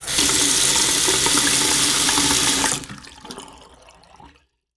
Sink Fill 02
Sink Fill 02.wav